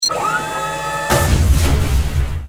bolt.wav